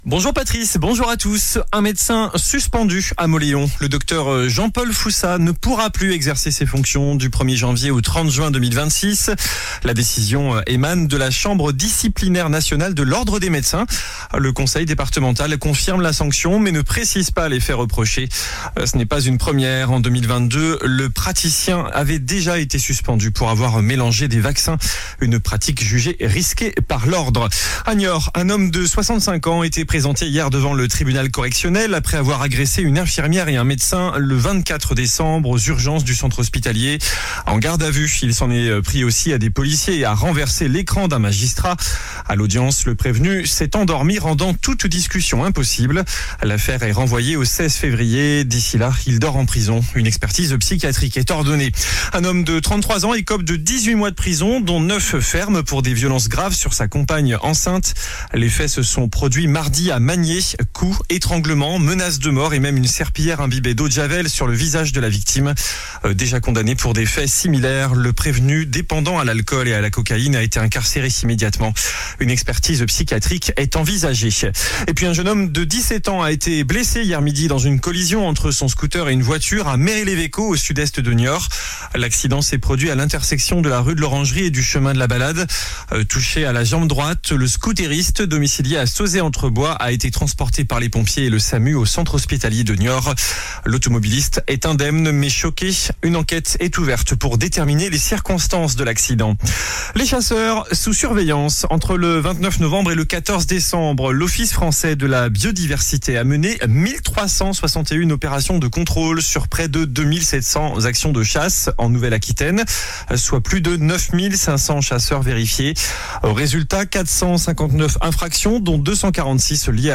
JOURNAL DU SAMEDI 27 DECEMBRE